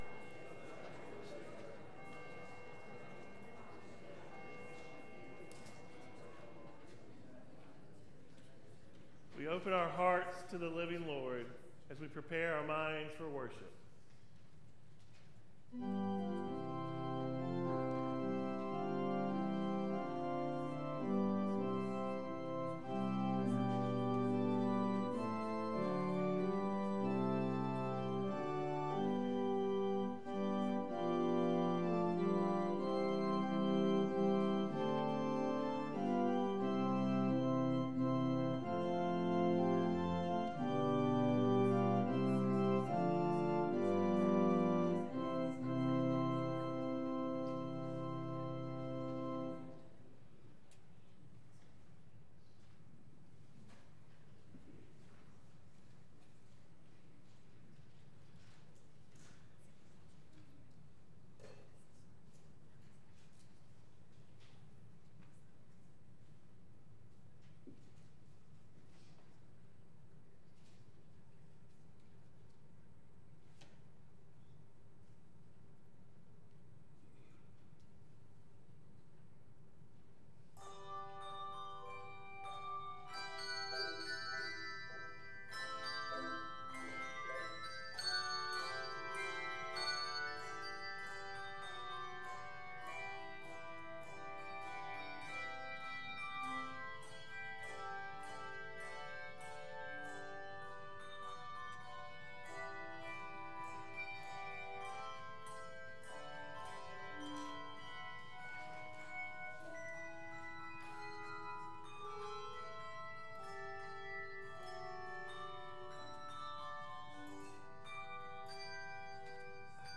1 Timothy 2:1-8 Service Type: Morning Bible Text